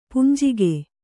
♪ punjige